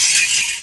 metal_scrape_2.wav